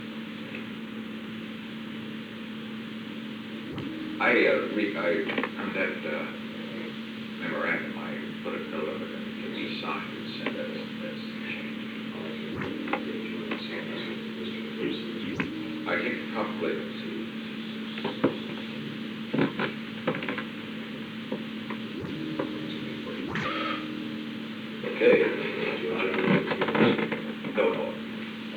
Secret White House Tapes
Conversation No. 429-20
Location: Executive Office Building